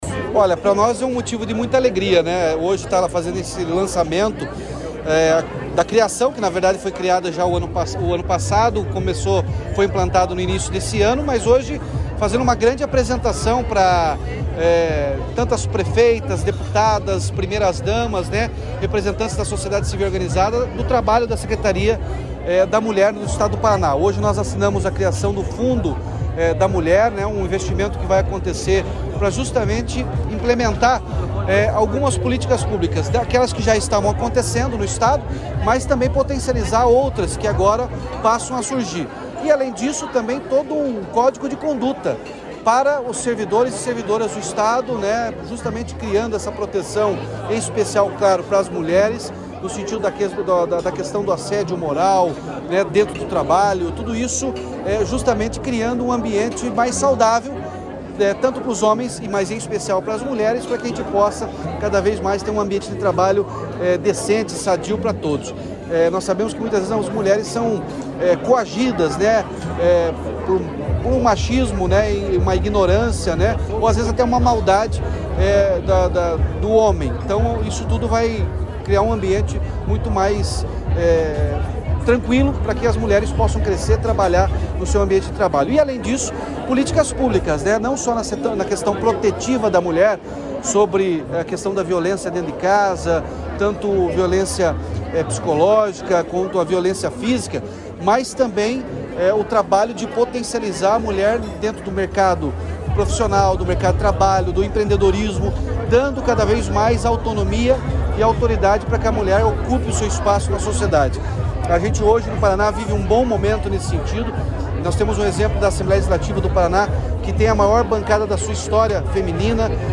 Sonora do Governador Ratinho Junior sobre o pacote de ações para as mulheres